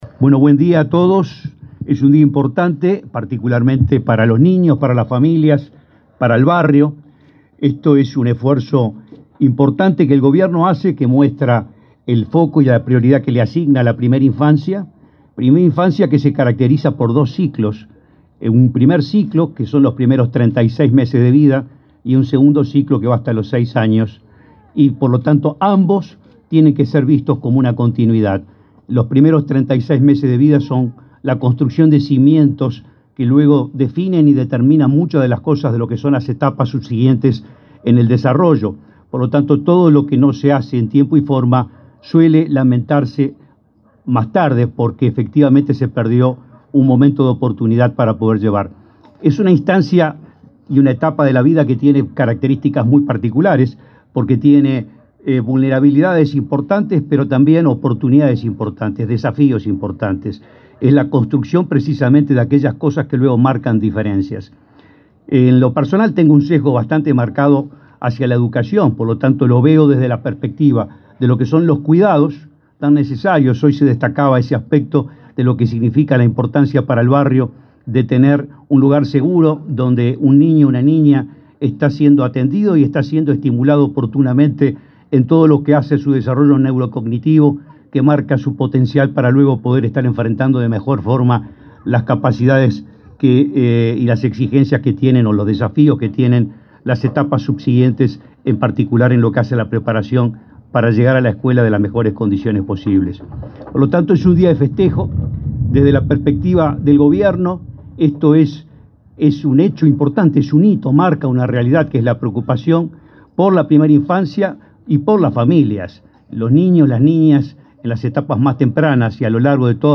Palabras del presidente del INAU, Guillermo Fossati
El presidente del Instituto del Niño y Adolescente del Uruguay (INAU), Guillermo Fossati, participó, este viernes 19 en Montevideo, de la inauguración